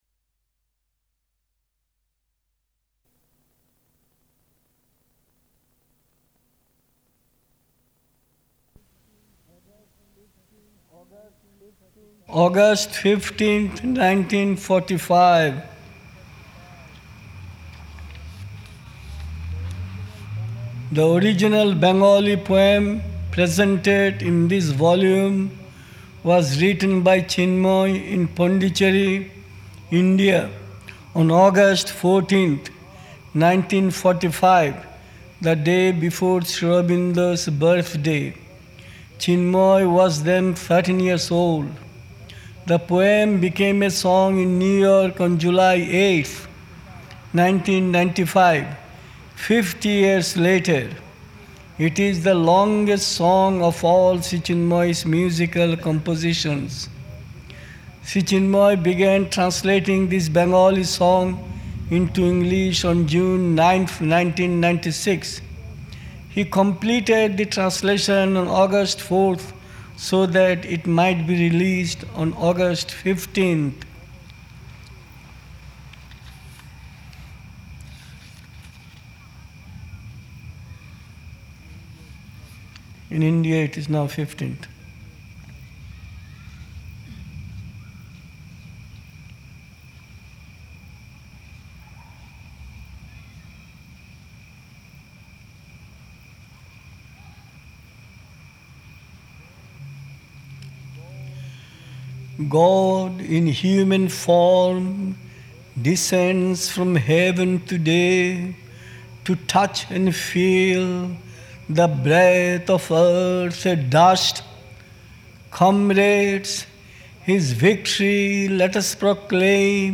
1. August 15 English Recital
august-15-english-translation-recital.mp3